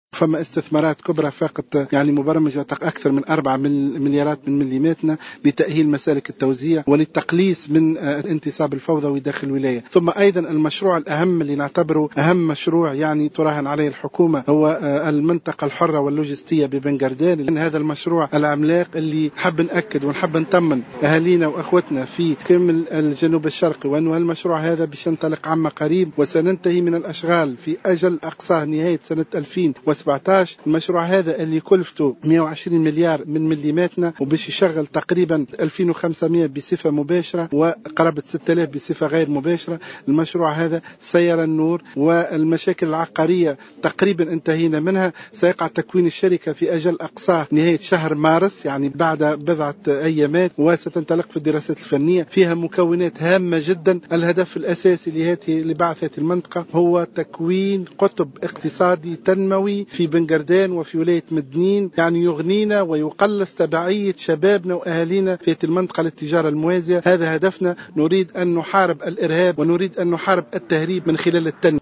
وزير التجارة